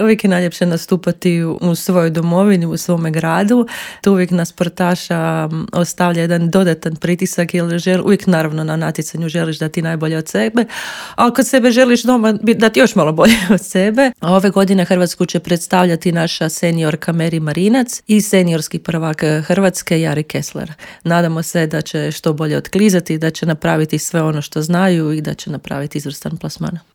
u studiju Media Servisa